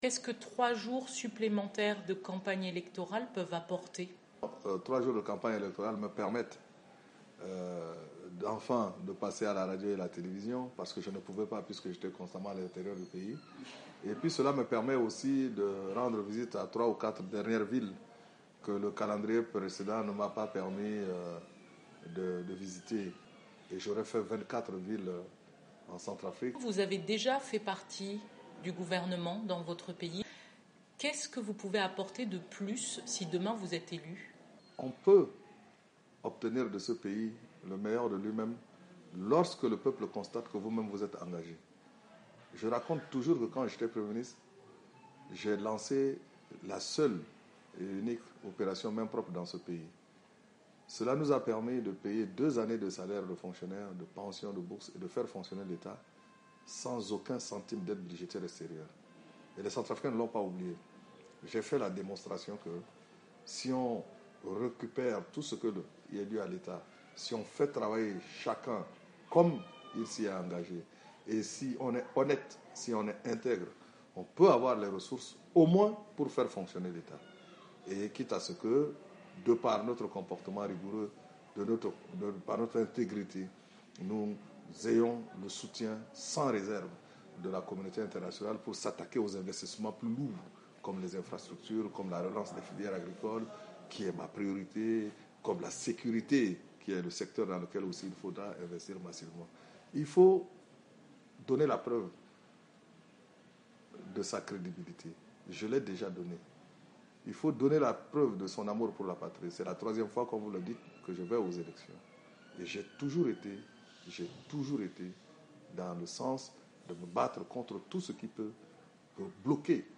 C'est sa troisieme campagne électorale. Il recoit VOA Afrique dans son domicile.